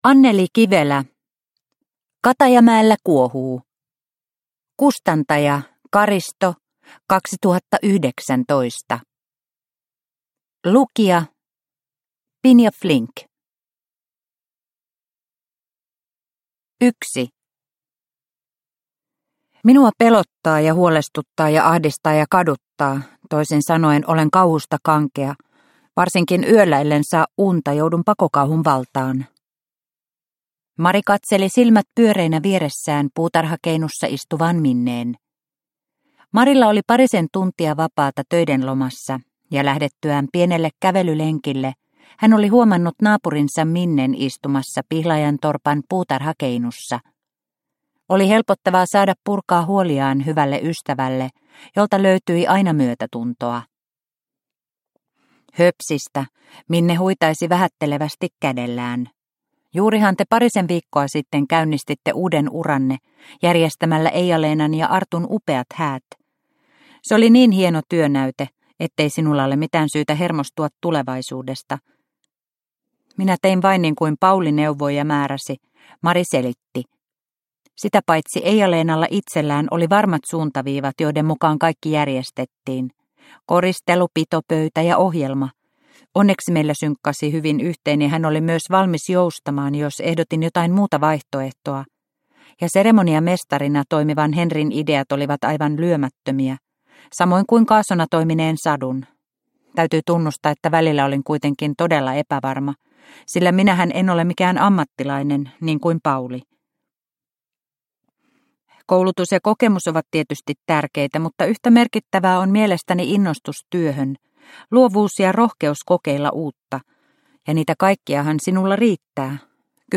Katajamäellä kuohuu – Ljudbok – Laddas ner